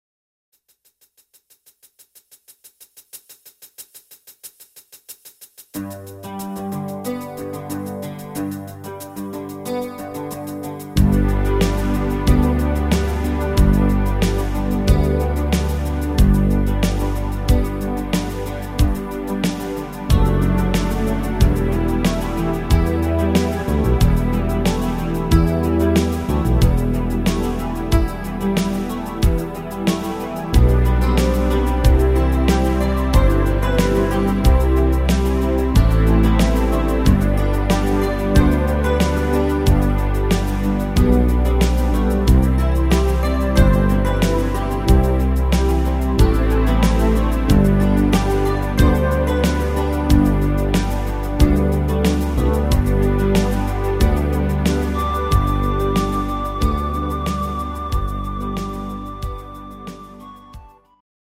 Gitarre